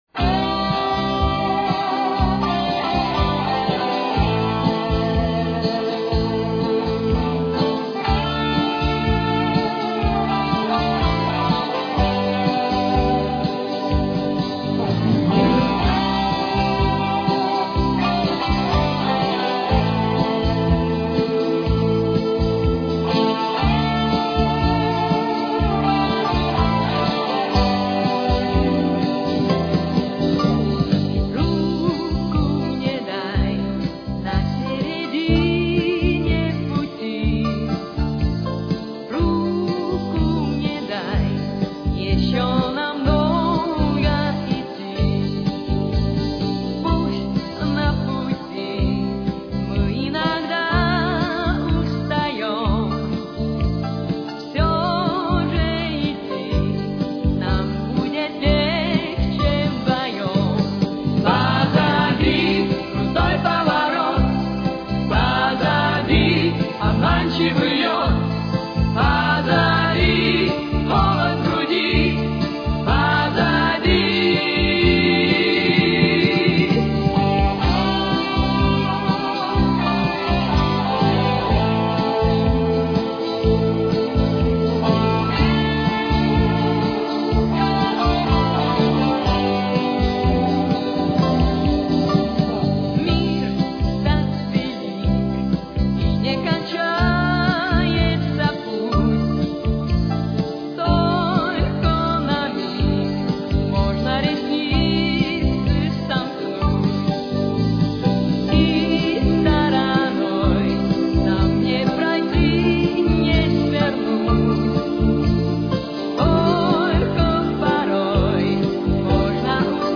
Темп: 126.